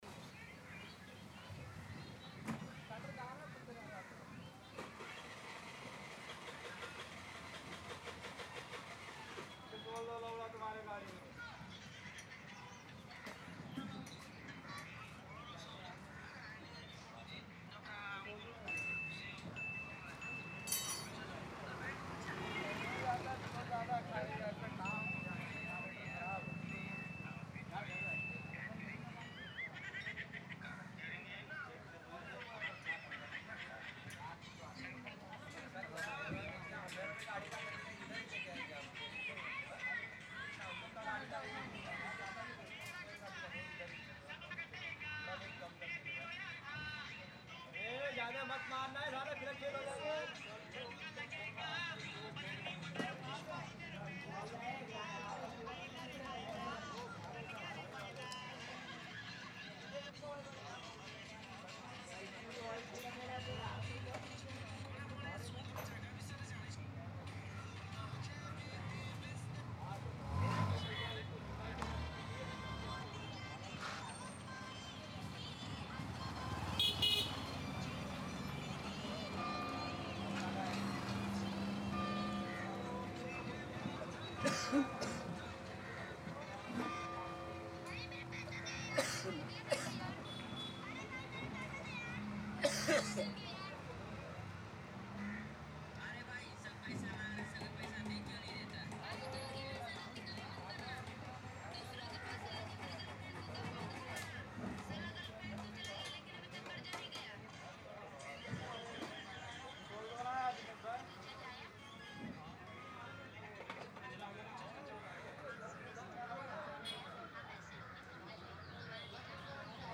Bike Start-AMB-036
Bike Starting ambience captures the crisp mechanical ignition of a motorcycle, featuring the starter motor crank, engine turnover, exhaust burst, and steady idling hum. The sound includes natural metallic clicks, throttle revs, and outdoor air tone, providing an authentic cinematic motorcycle start-up feel. Cleanly recorded with clear engine texture, this ambience is ideal for adding real-world vehicle realism to scenes without overpowering dialogue.
Vehicle / Mechanical
Motorcycle Ignition
Outdoor Close-Up
Thirtysix-bike-kick.mp3